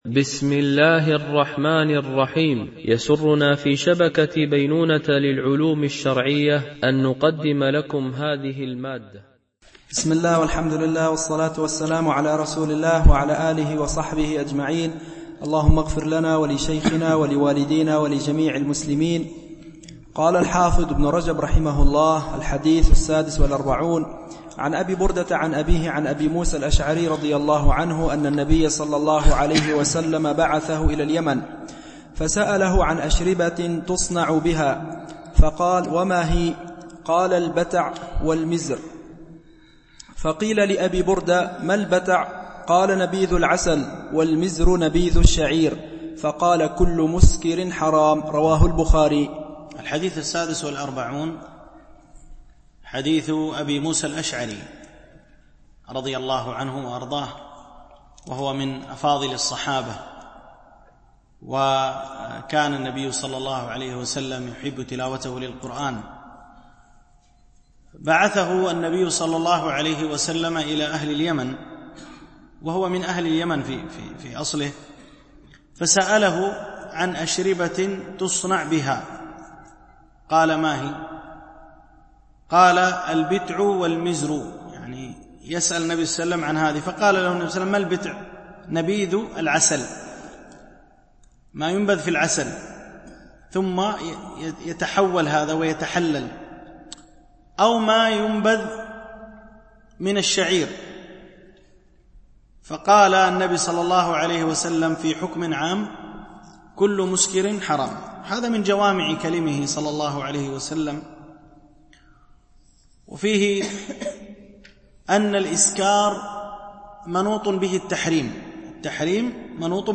شرح الأربعين النووية - الدرس 21 ( الحديث 46 ـ 50 )
التنسيق: MP3 Mono 22kHz 32Kbps (CBR)